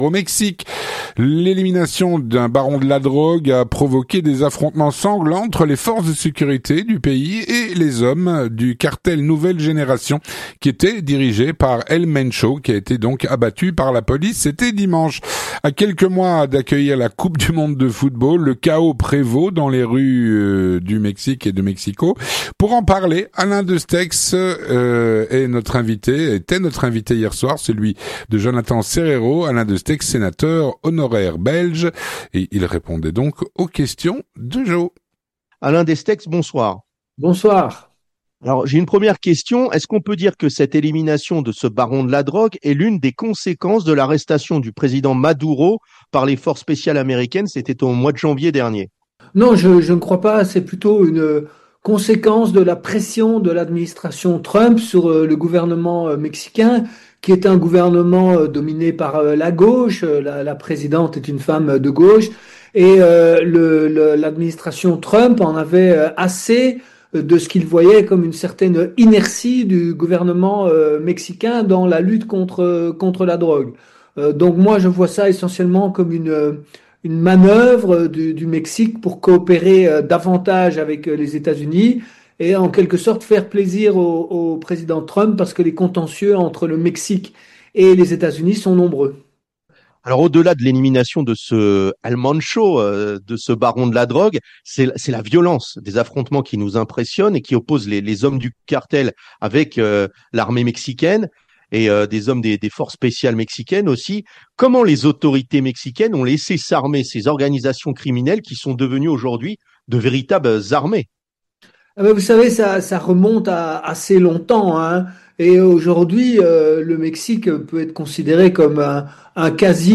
L'entretien du 18h
On en parle avec Alain Destexhe, sénateur honoraire belge.